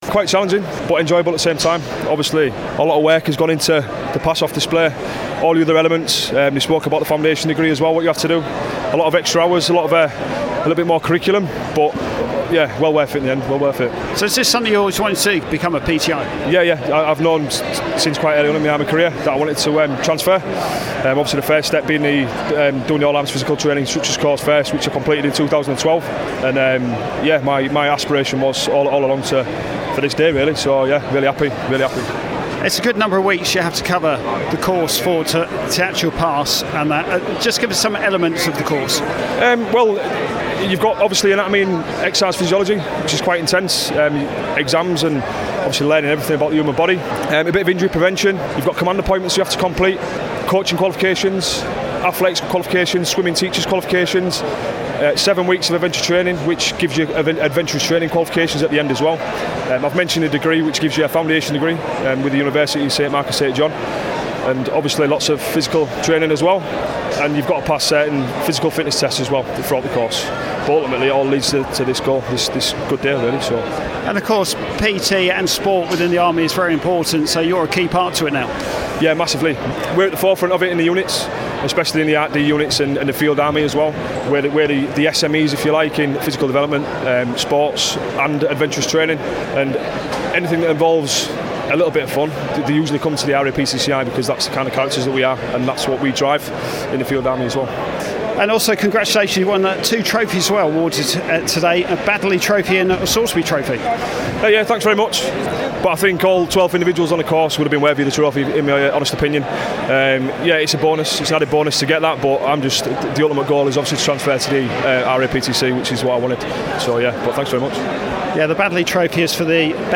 The Royal Army Physical Training Corps Instructors (course No 203) and All Arms Physical Training Instructors (course No 44) have for the last time in 2015 qualified at a Pass out Parade held at Fox Lines, Queens Avenue, Aldershot.